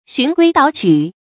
注音：ㄒㄩㄣˊ ㄍㄨㄟ ㄉㄠˇ ㄉㄠˇ ㄉㄠˇ
讀音讀法：
循規蹈矩的讀法